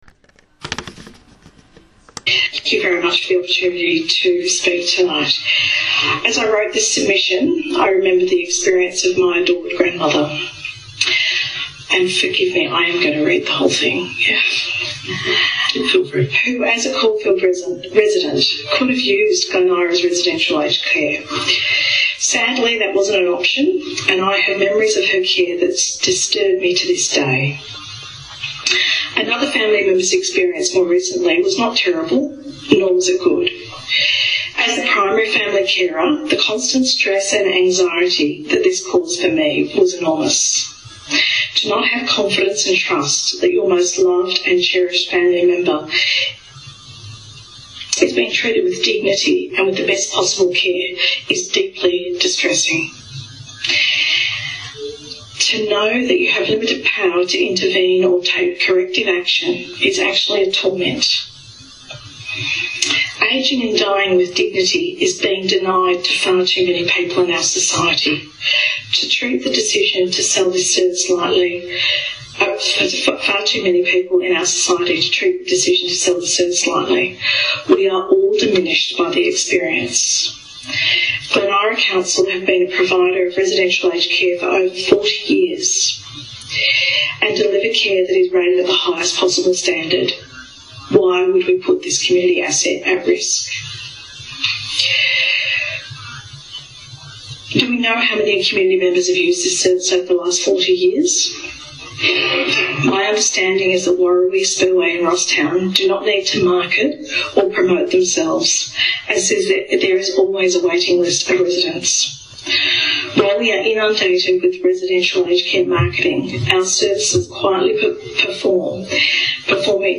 Residents addressed council tonight on the proposed sell off of our aged care facilities.
We present below one resident’s submission. Please listen to this carefully since it summarises beautifully how council has failed its community and the lack of transparency.